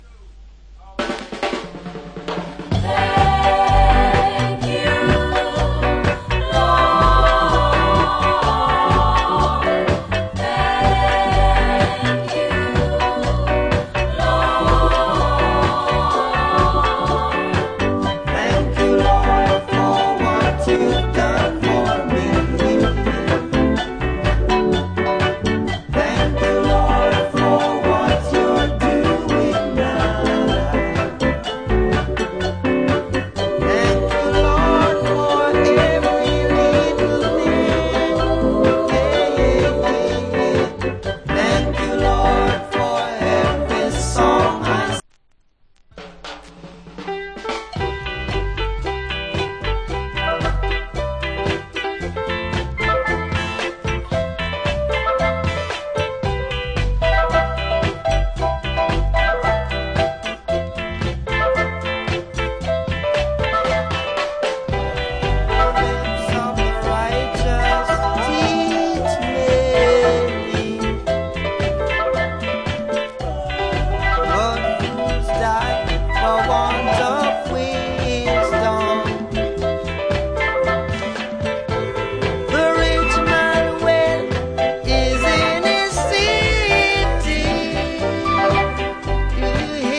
Wicked Early Reggae Vocal.